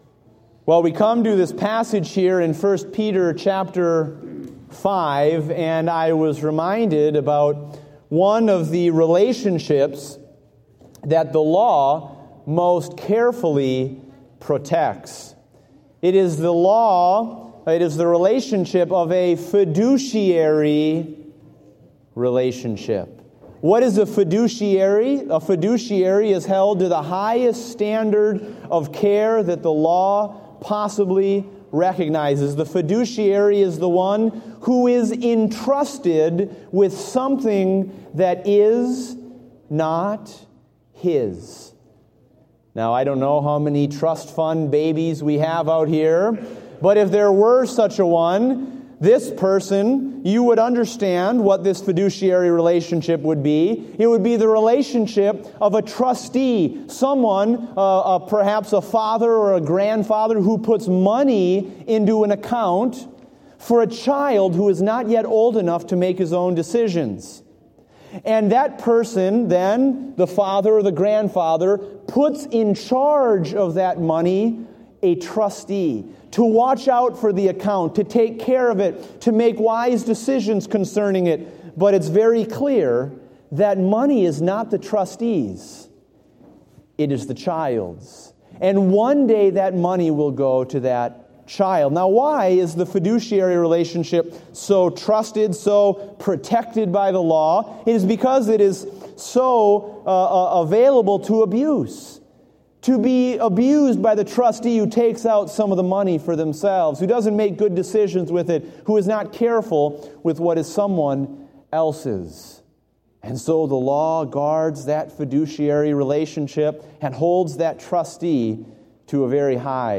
Date: December 6, 2015 (Morning Service)